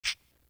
Dub Shaker.wav